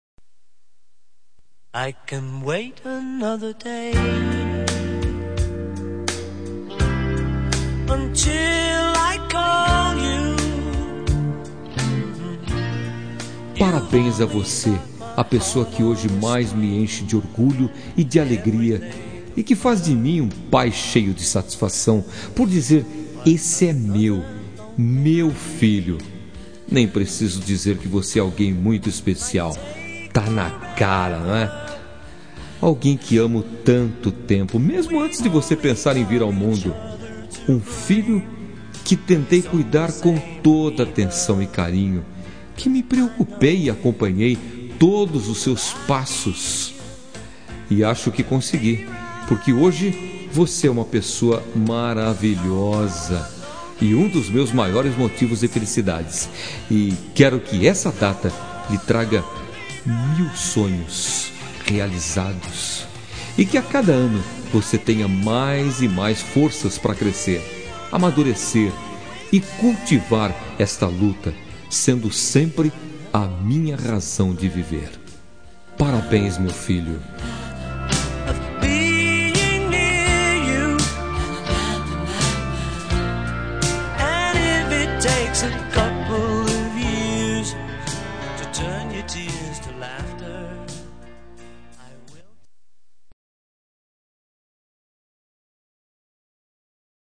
Telemensagem de Aniversário de Filho – Voz Masculina – Cód: 1850 Bonita